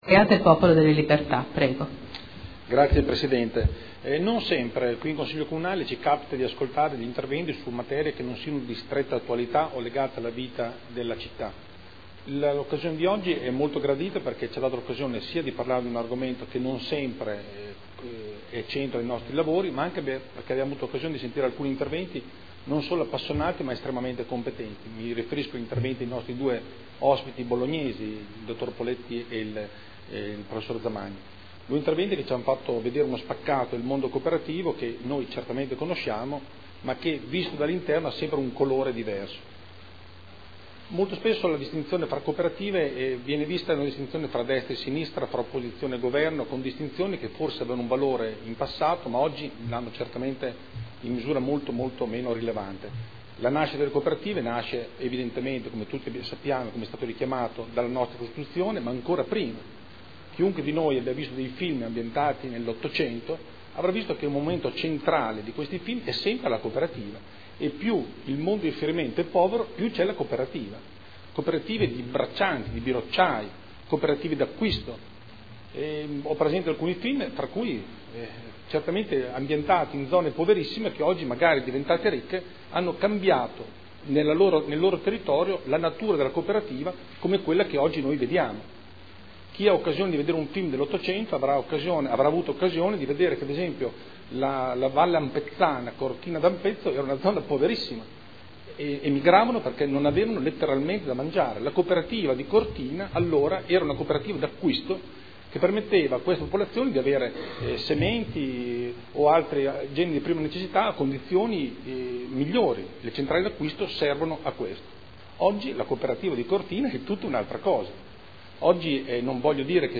Seduta del 22/11/2012. Dibattito su celebrazione dell’Anno internazionale delle cooperative indetto dall’ONU per il 2012